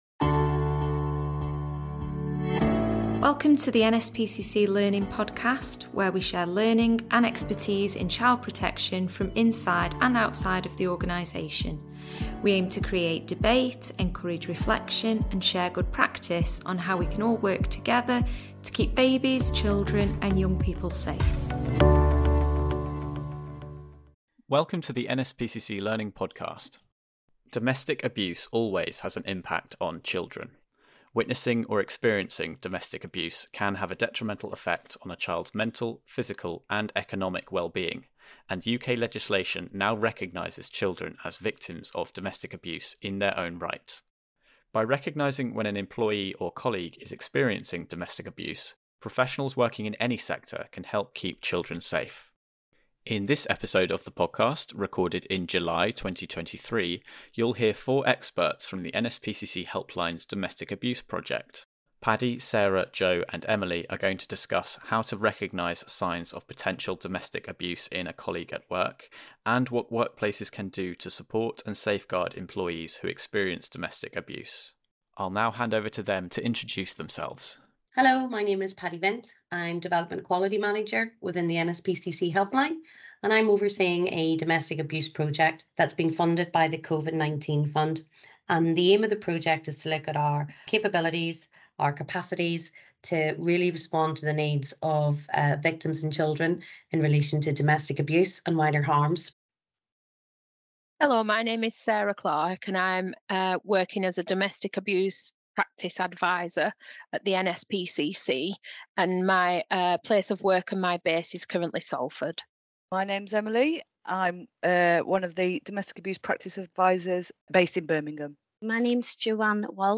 Domestic abuse experts from the NSPCC Helpline discuss what workplaces can do to provide support to parents and children experiencing domestic abuse.